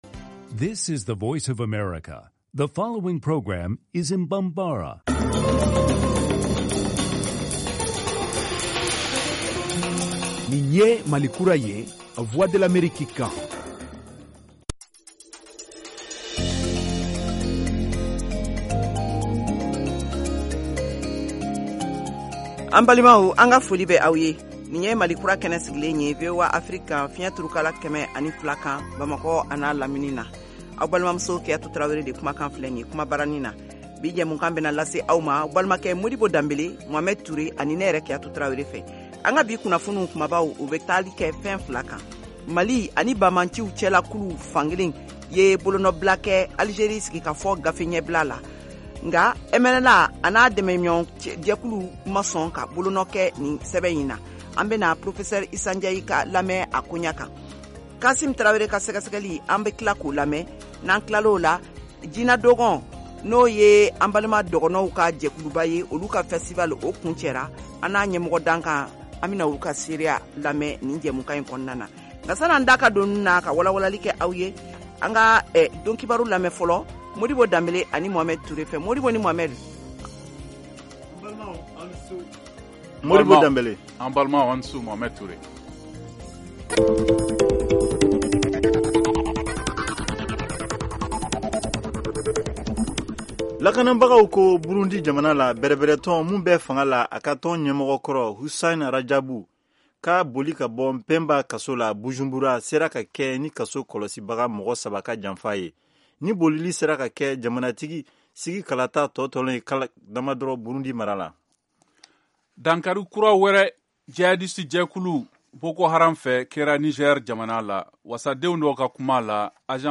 Emission quotidienne en langue bambara
en direct de Washington, DC, aux USA